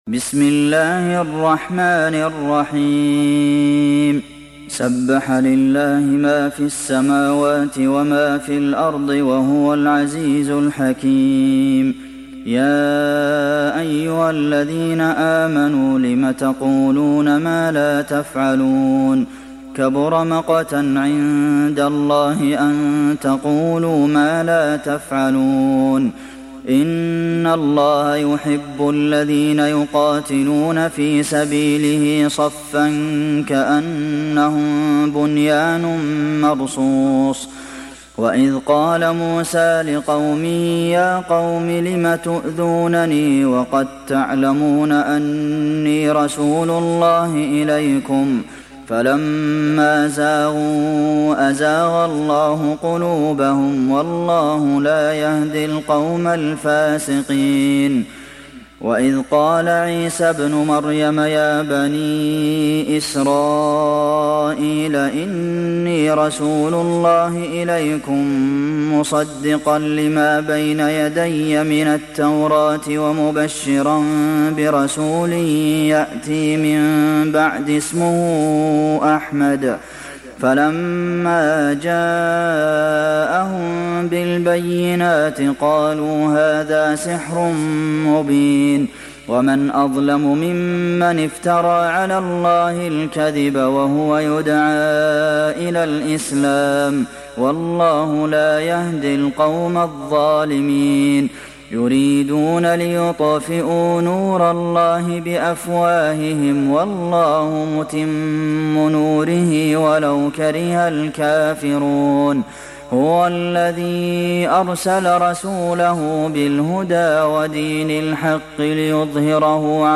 دانلود سوره الصف mp3 عبد المحسن القاسم روایت حفص از عاصم, قرآن را دانلود کنید و گوش کن mp3 ، لینک مستقیم کامل